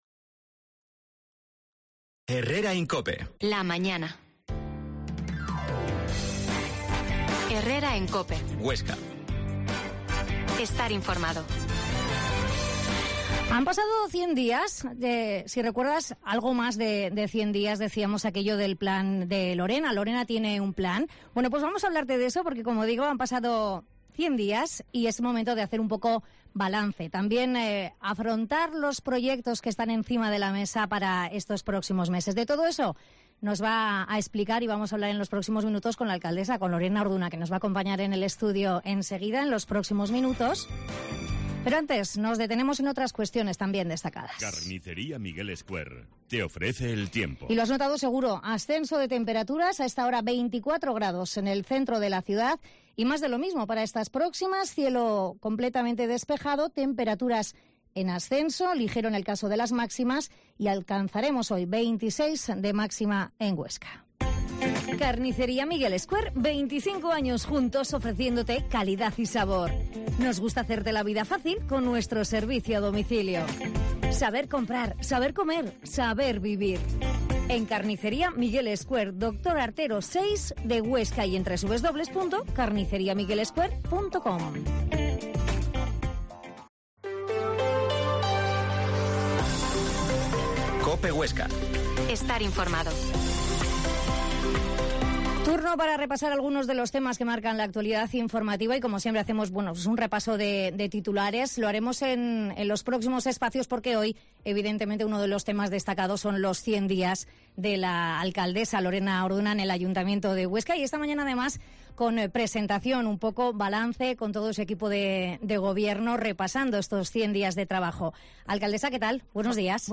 Herrera en COPE Huesca 12.50h Entrevista a la alcaldesa de Huesca Lorena Orduna